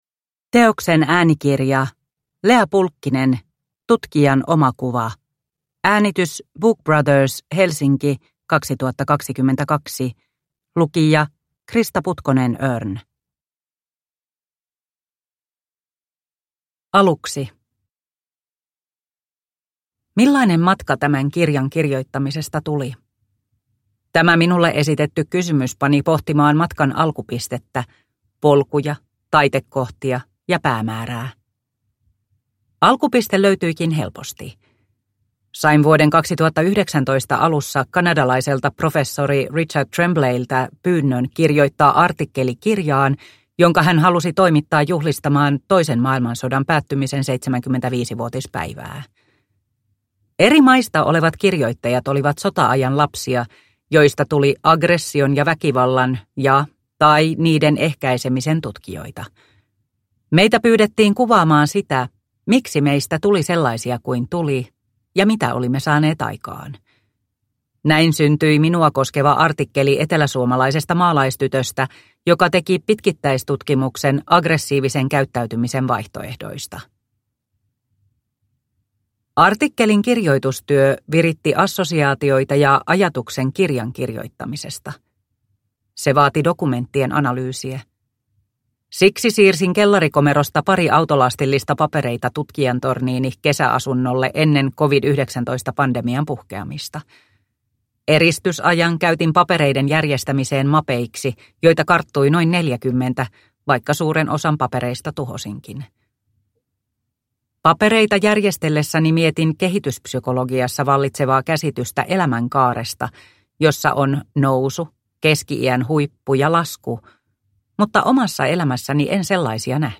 Tutkijan omakuva – Ljudbok – Laddas ner